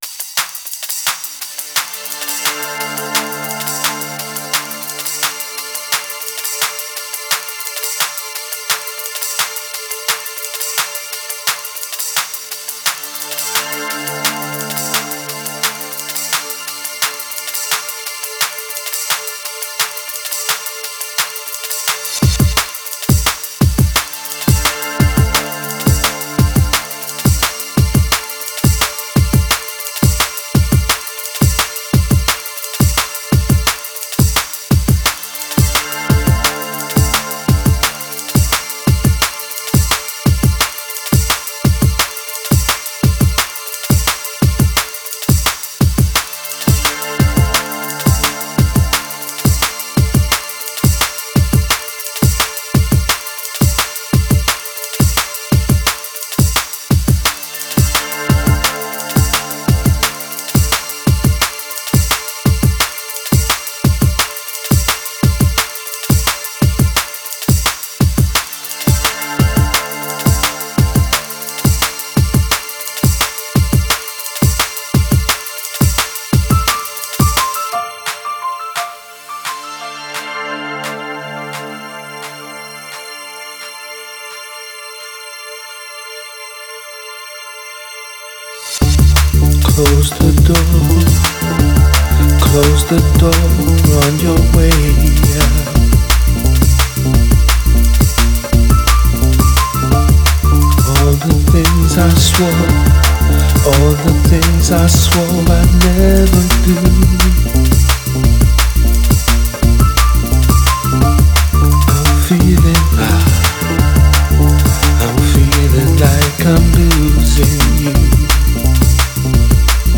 Style: Drum & Bass